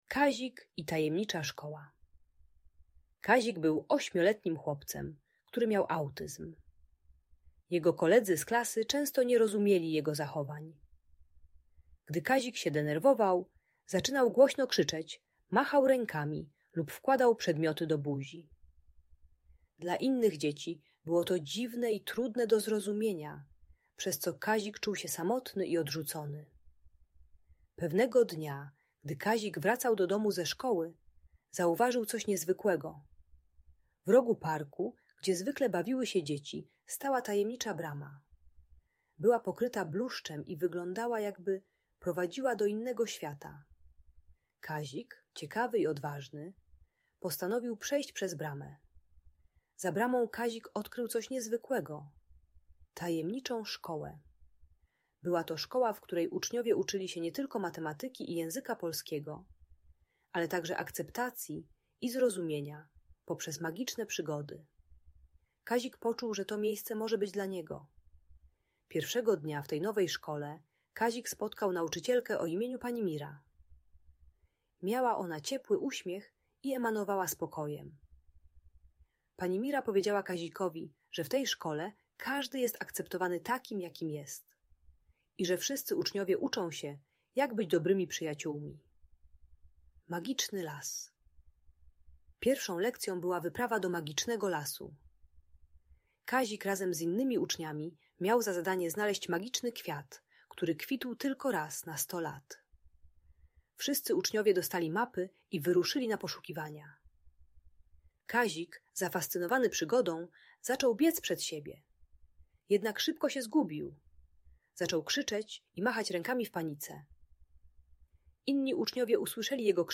Kazik i Tajemnicza Szkoła - Audiobajka dla dzieci